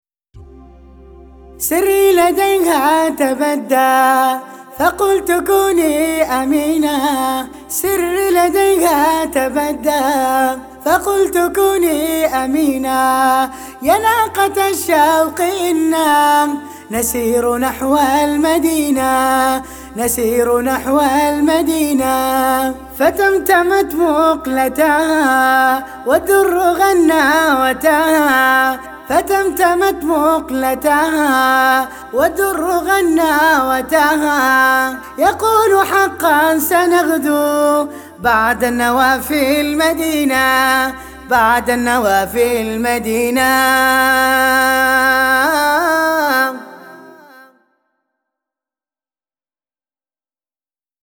رست